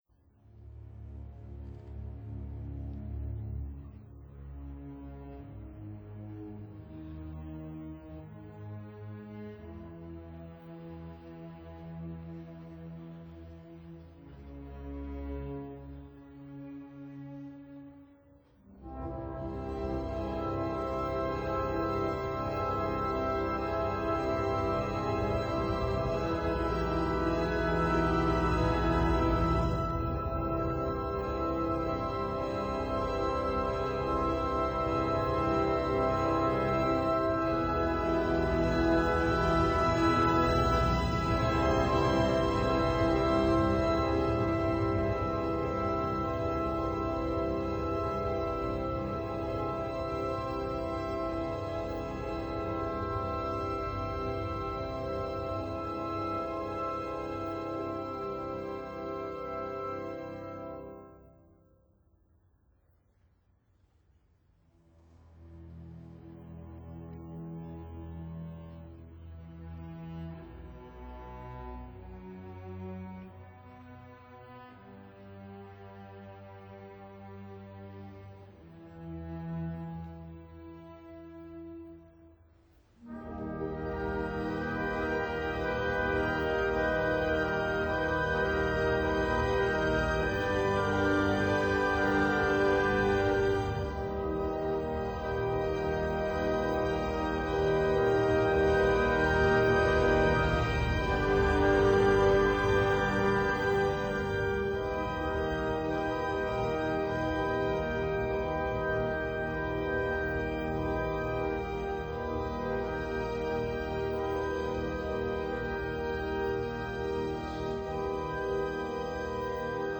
piano
percussion